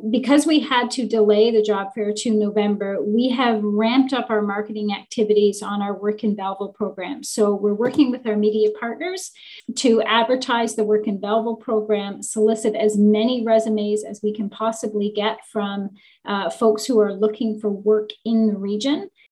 At Wednesday’s meeting of the Economic and Destination Development Committee